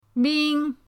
ming1.mp3